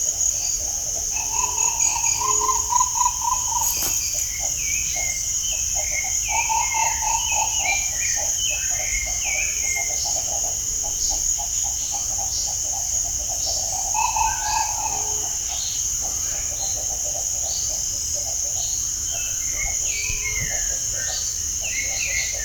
Tapicurú (Mesembrinibis cayennensis)
Nombre en inglés: Green Ibis
Fase de la vida: Adulto
Localidad o área protegida: Reserva Privada y Ecolodge Surucuá
Condición: Silvestre
Certeza: Vocalización Grabada
Tapicuru.mp3